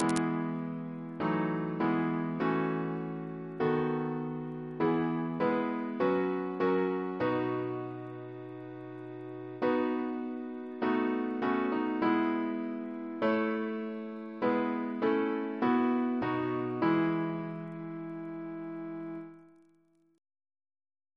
Double chant in E Composer: John Foster (1827-1915) Reference psalters: ACB: 52; ACP: 195; CWP: 97; RSCM: 53